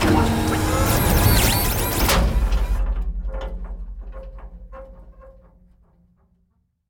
dockingbaydock.wav